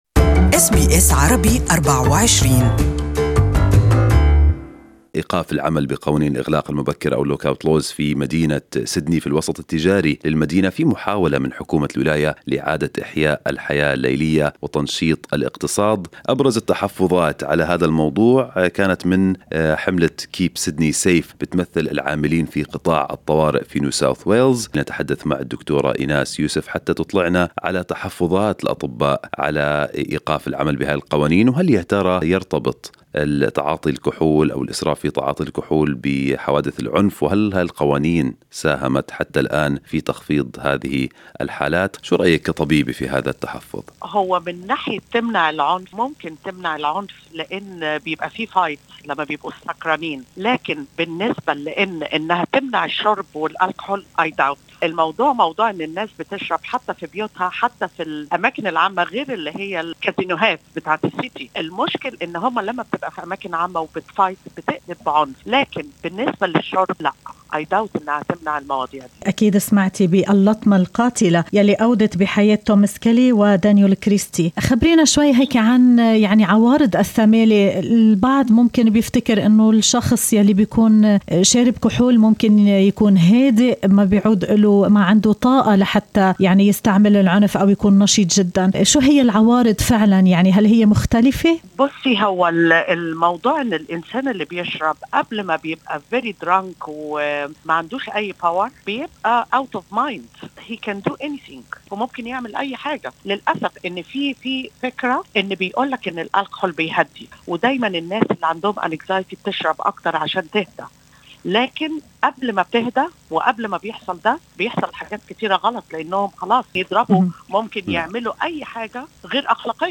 ضابطة في شرطة فكتوريا وطبيبة تتحدثان عن جدوى قانون الإغلاق المبكر للنوادي الليلية في سيدني.